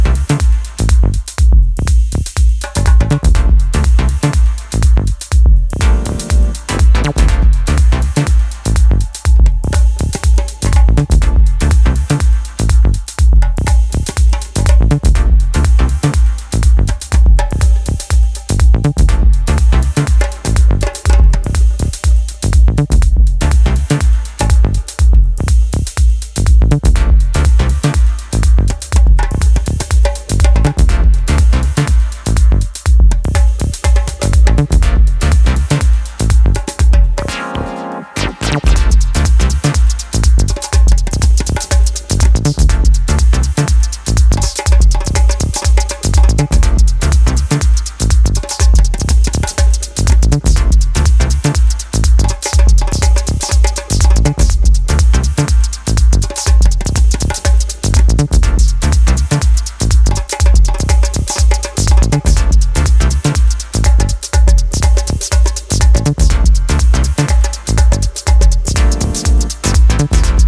jazzy house workout